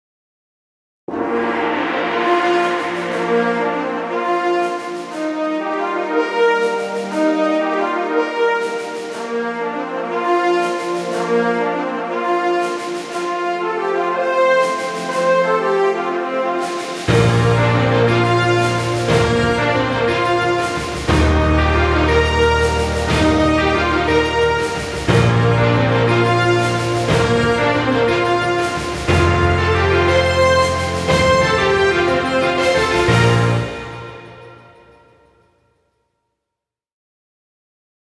Fanfare music.